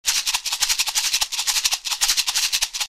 They have a very dry sound, and in the hands of the best Salseros, can create the rhythmic drive to propel the most dynamic bands.
LP Rawhide Maracas are designed with a narrow shell for tighter response.
These maracas have a slightly lower pitch than wooden maracas, and the rattles produce a warmer, richer sound.